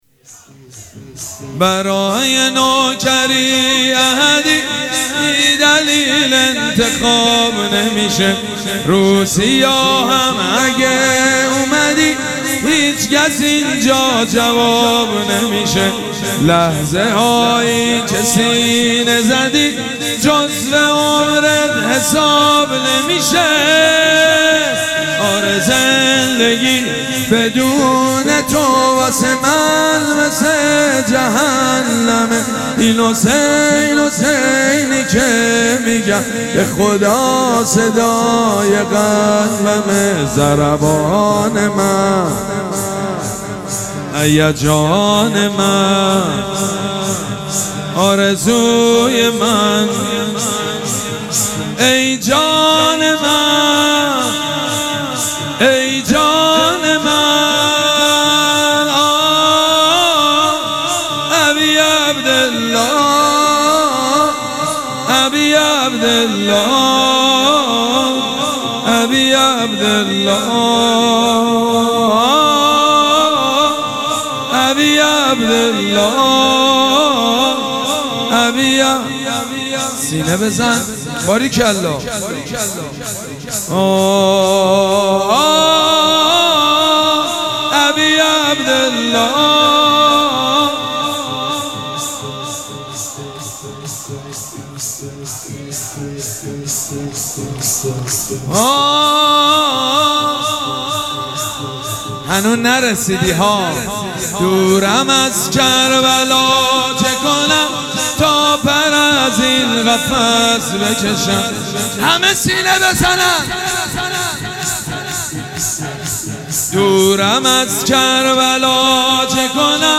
شب اول مراسم عزاداری اربعین حسینی ۱۴۴۷
موکب ریحانه الحسین سلام الله علیها
شور
مداح
حاج سید مجید بنی فاطمه